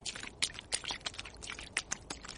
runningAudioWater.mp3